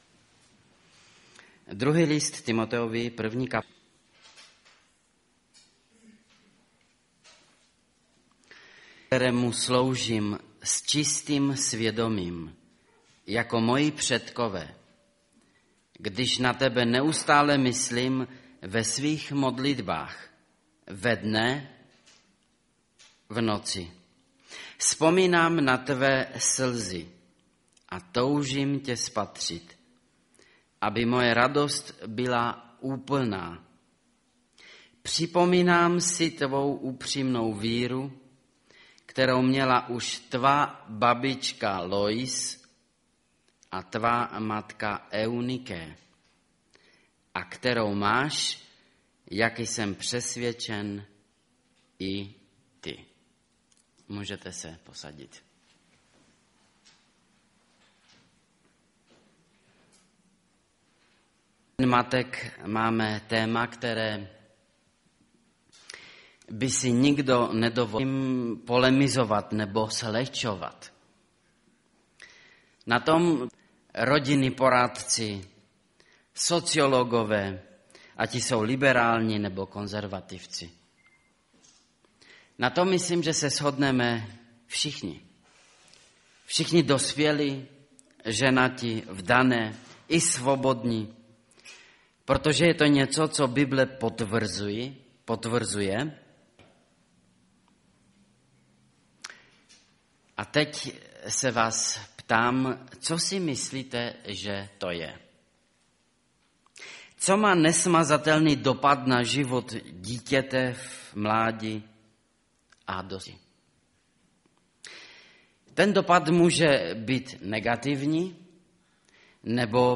Největší radost maminky Kategorie: Kázání MP3 Zobrazení: 2878 Den matek -největší radost maminky. Inspirativni slovo pro rodiče.